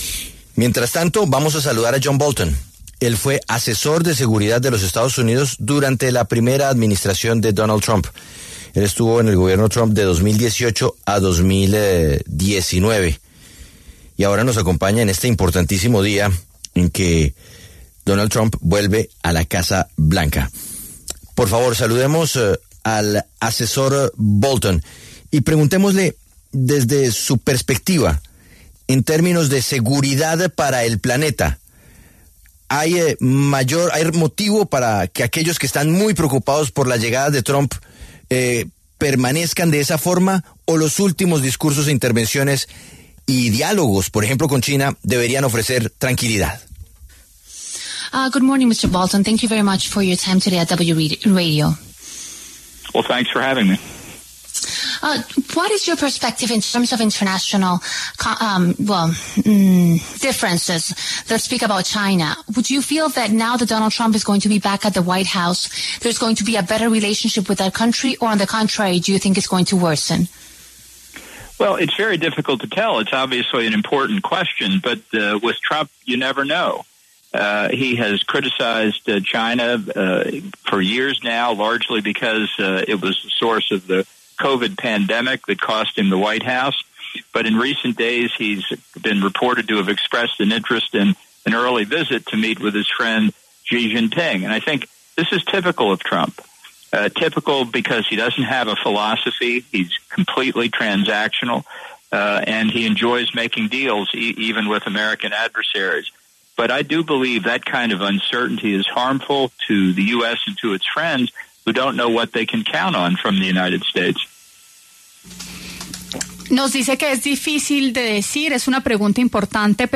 John Bolton habló para La W sobre lo que vendría para Estados Unidos con un nuevo mandato de Trump.
John Robert Bolton, exasesor de seguridad de EE.UU. (de 2018 a 2019, en la administración Trump), pasó por los micrófonos de La W y entregó detalles de lo que vendría para ese país con el nuevo mandato del republicano.